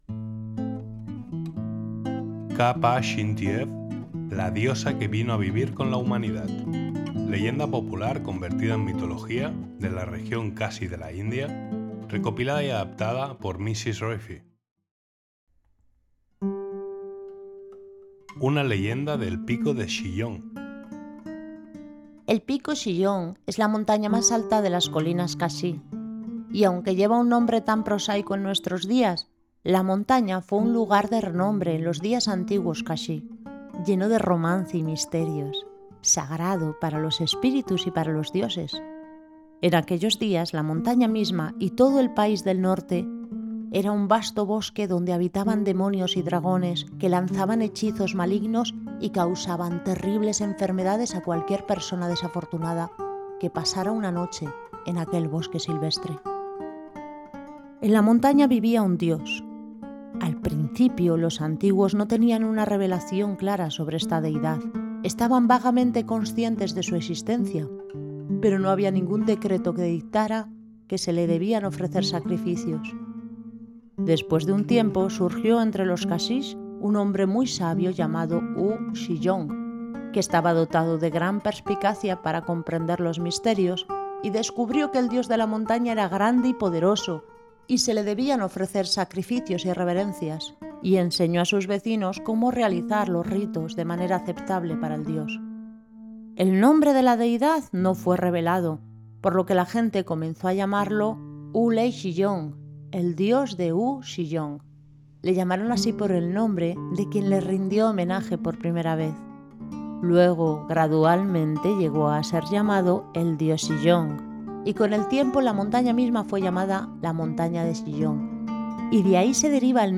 Narraciones con voz humana en español castellano